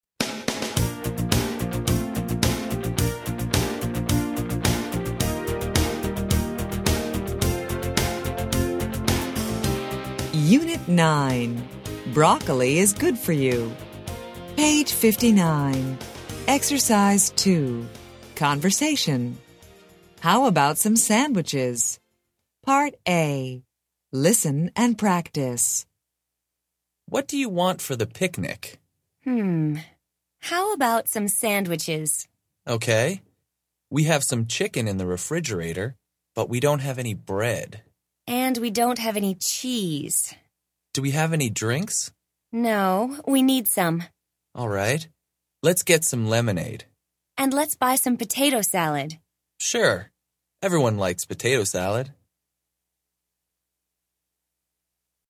Interchange Third Edition Intro Unit 9 Ex 2 Conversation Track 25 Students Book Student Arcade Self Study Audio
interchange3-intro-unit9-ex2-conversation-track25-students-book-student-arcade-self-study-audio.mp3